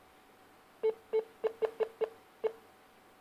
• ▲ ▼ Moin Seit einigen Tagen gibt mein Notebook (Samsung 700G7) alle paar Minuten mehr oder weniger die selben Beepsignale von sich.
beeping.mp3